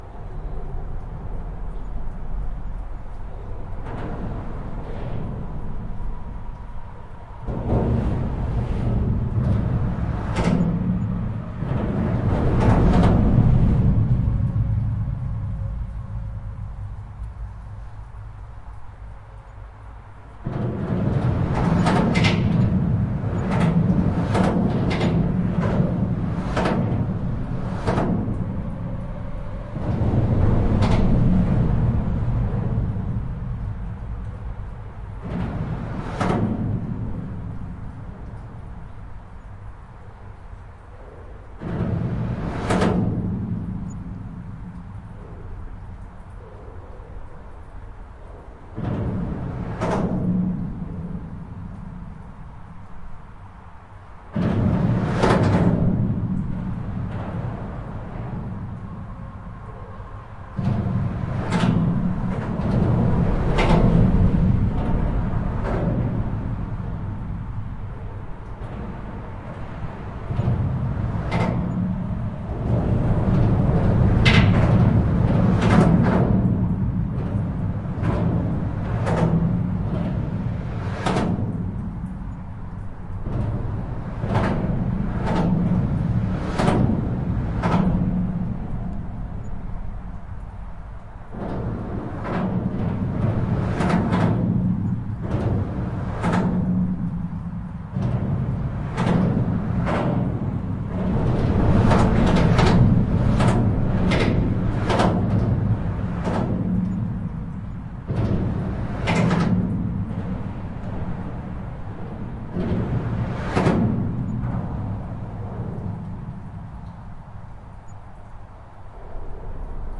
描述：桥梁地下过道交通街道环境都市高速公路背景影片foley气氛大气soundscape噪声领域录音背景声音atmos
标签： 高速公路 背景声 音景 氛围 环境 背景 电影 交通 街道 ATMOS 城市 现场录音 地下通道 噪音 大气 桥梁 弗利
声道立体声